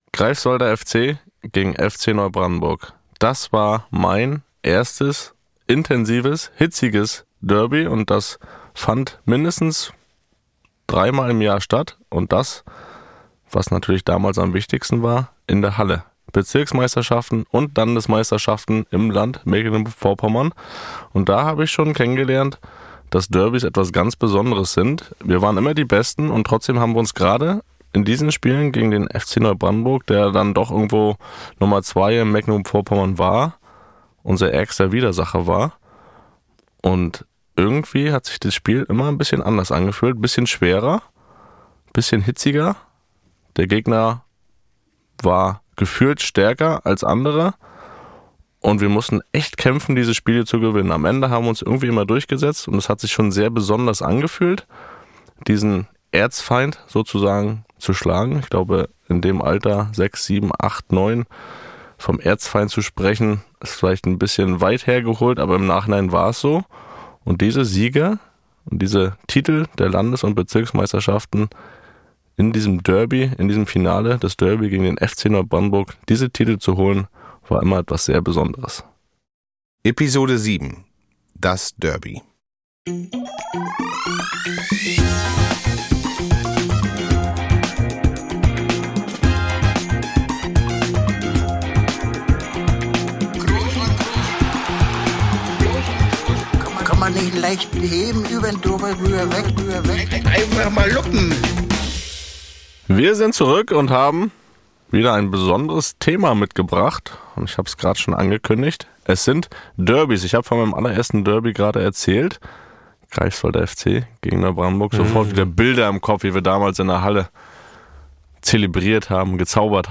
Die beiden Brüder besprechen in ihrem neuen, gemeinsamen Podcast alles, was sie bewegt: Den letzten Freistoß, Familien-Eskapaden im Schrebergarten, Begegnungen mit Robbie Williams, wie Cristiano Ronaldo wirklich ist und warum sich Angela Merkel bislang nicht getraut hat, Toni anzurufen. In “Einfach mal luppen” sind die Hörer*innen in der Telefonleitung der Kroos Brüder, die sich viel zu selten sehen – deshalb aber umso mehr zu sagen haben.